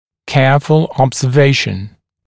[‘keəfl ˌɔbzə’veɪʃn] [-ful][‘кеафл ˌобзэ’вэйшн] [-фул]тщательное наблюдение